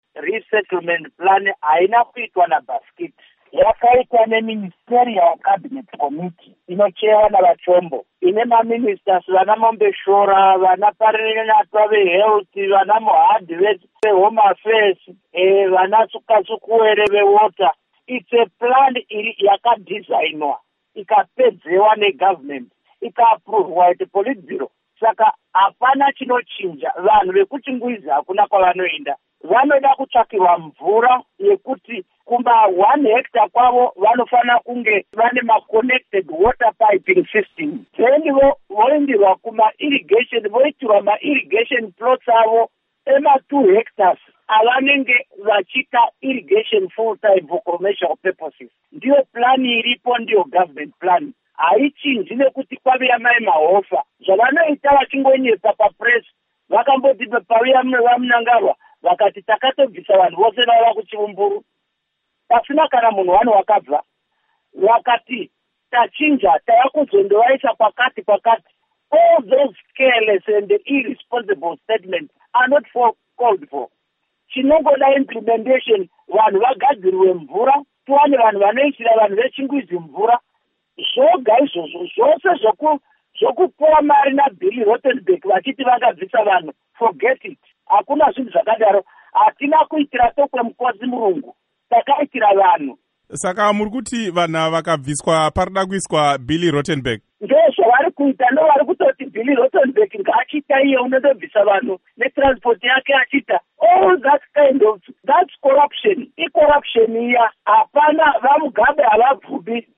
Hurukuro naVaKudakwashe Bhasikiti